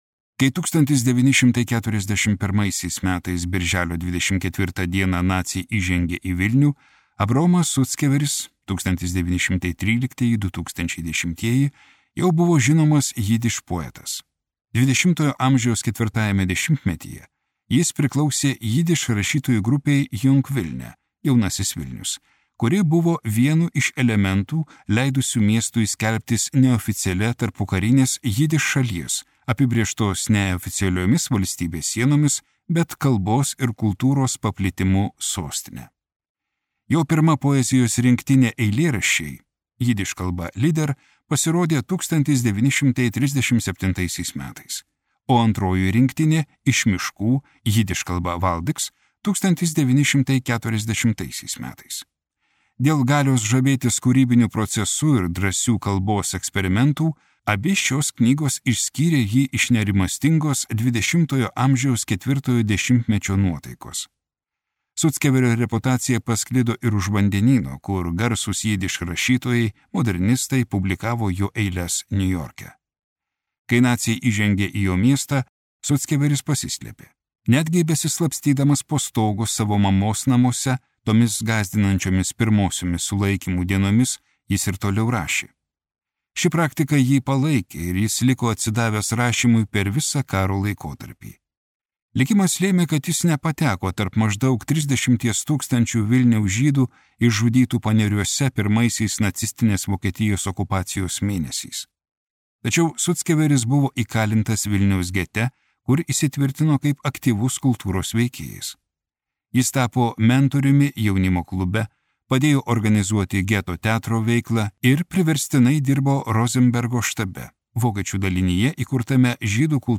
Nuo Vilniaus iki Niurnbergo | Audioknygos | baltos lankos